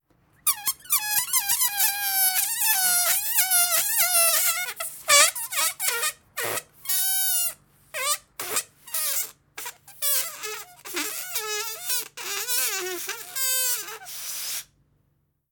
Efecto cómico de desinflado de un globo (trompetilla)